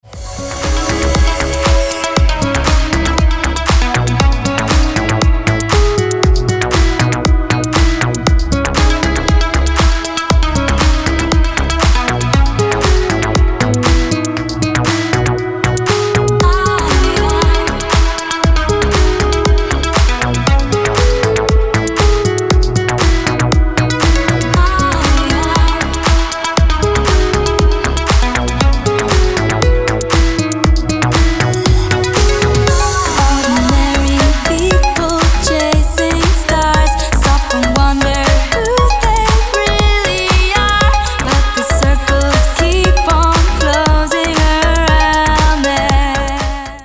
Дип Хаус мотивы на гаджет